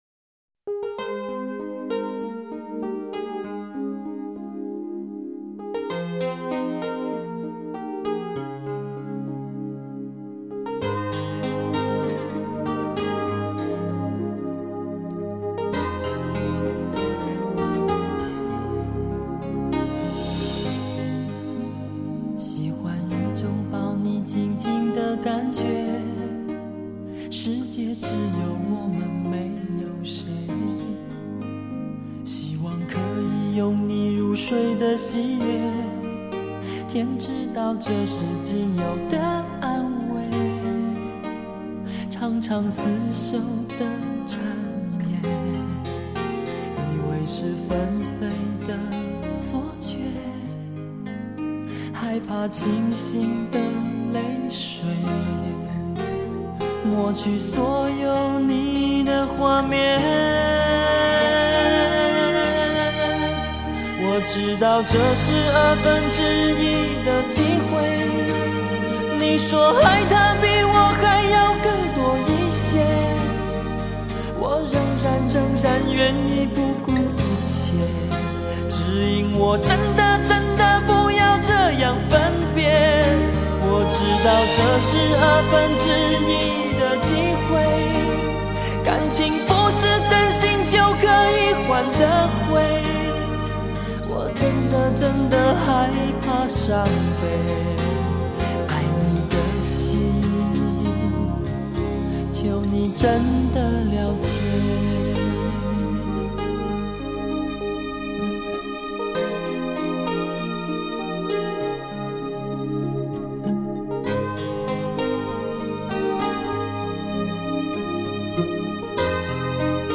清纯的声音